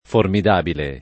formidabile [ formid # bile ] agg.